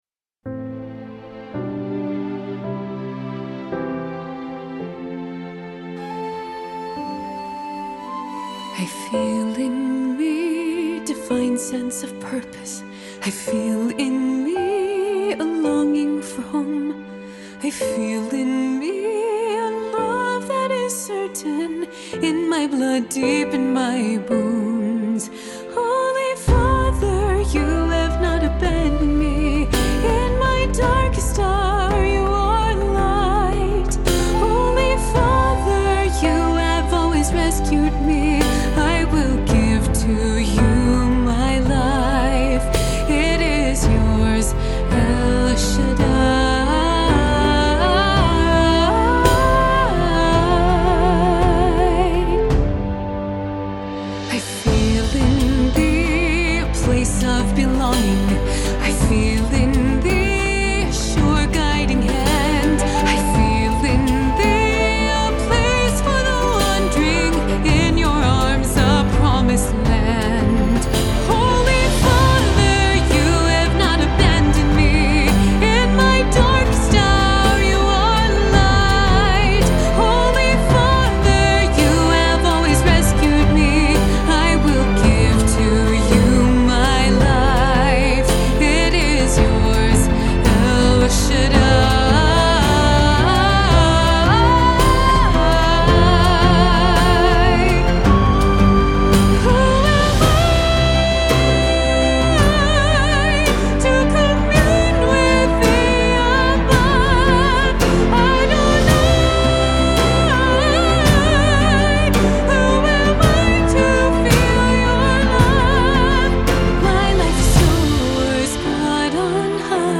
This musical seeks to present that story with beauty, emotional depth, and reverence, blending cinematic storytelling with music that invites audiences to feel the weight of Esther’s decision and the quiet power of faith.
In Your Hands, El Shaddai – Esther taken to palace